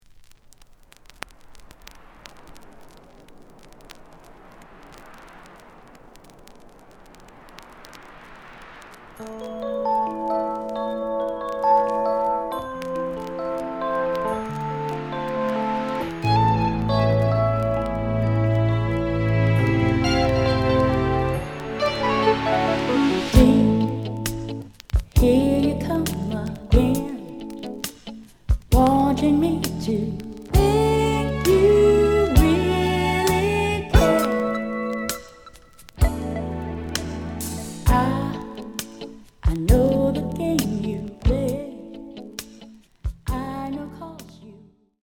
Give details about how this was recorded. The audio sample is recorded from the actual item. Looks good, but slight noise on both sides.)